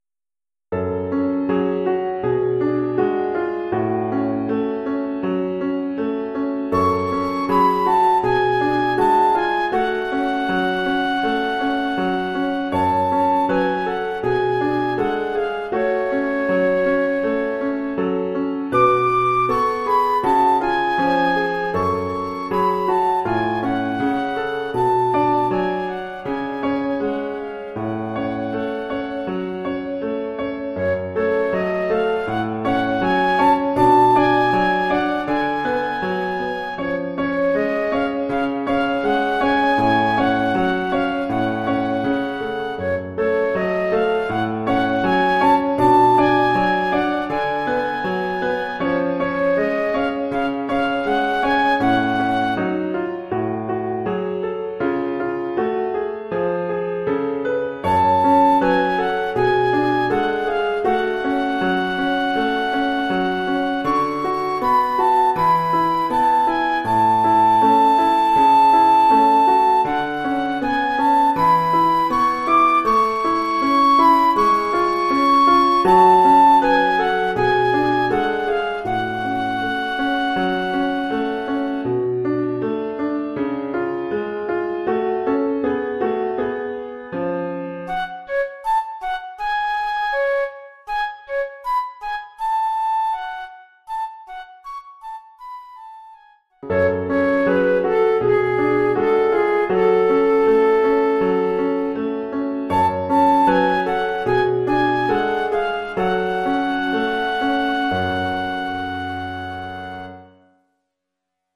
Formule instrumentale : Flûte et piano
Oeuvre pour flûte et piano.